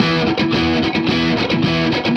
AM_HeroGuitar_110-E02.wav